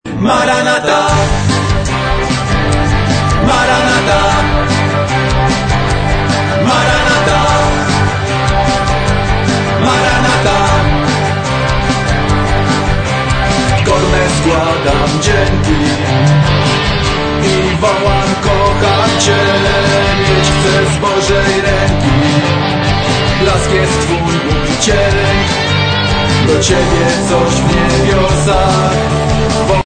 Mocno elektryczna i energetyczna.